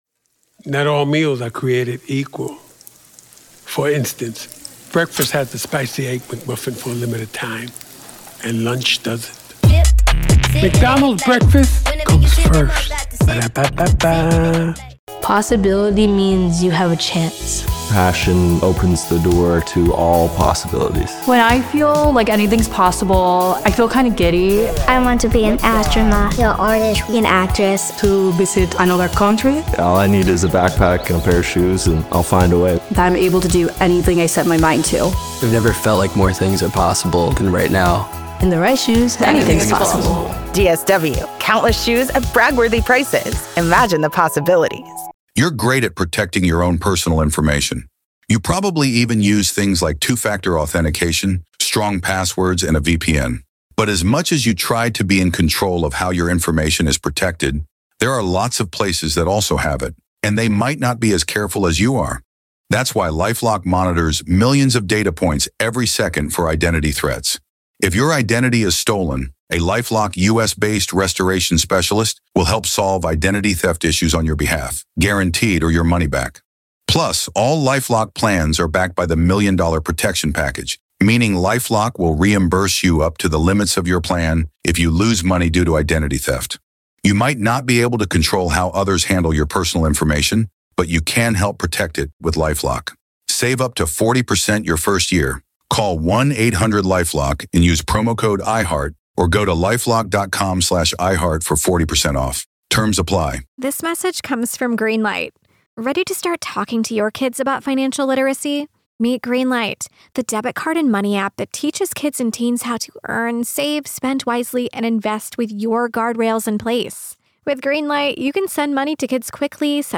The conversation delves into the strategic decisions made by the defense and the implications of these decisions on the future of the case. **Main Points of the Conversation:** - Discussion on the likelihood of conspiracy charges being more successful against Chad Daybell due to the lack of direct evidence linking him to the murders.